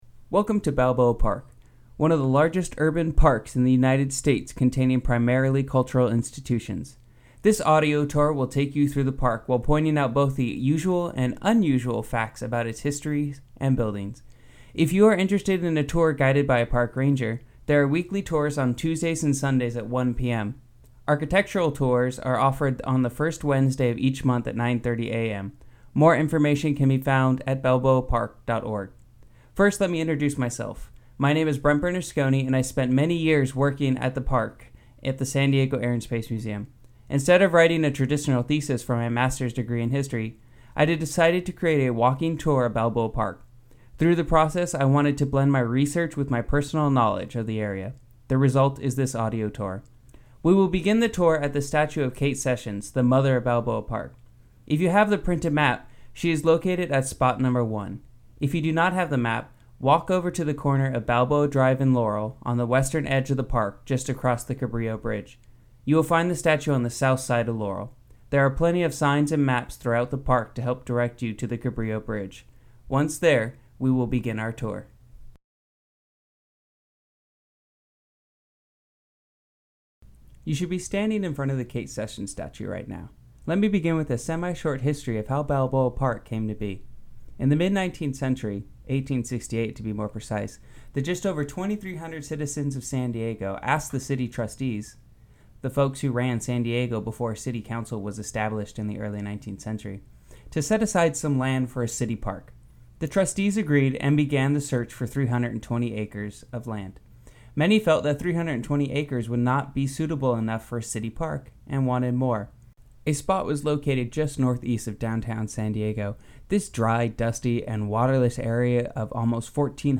Walking Through History: Balboa Park An Audio Guide Tour